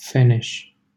Audio pronunciation file from the Lingua Libre project.